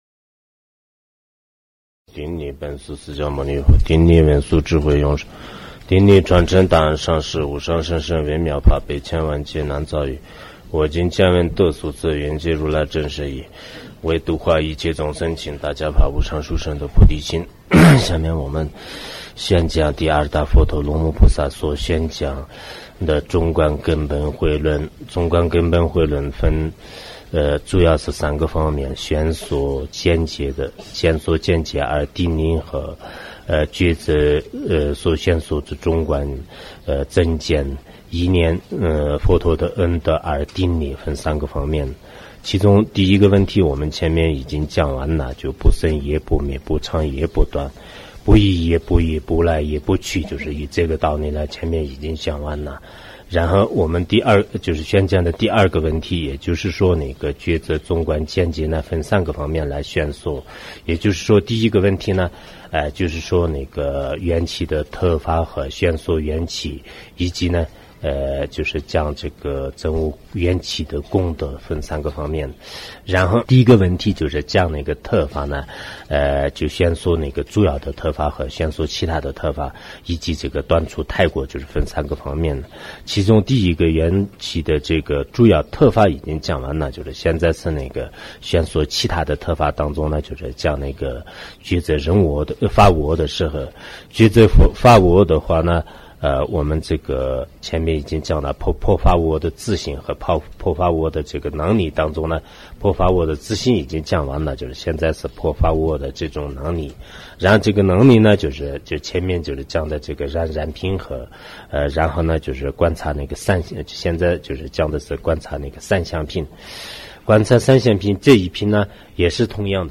中论讲解 龙树菩萨 ·造论 麦彭仁波切 · 注释 索达吉堪布 ·译讲 顶礼本师释迦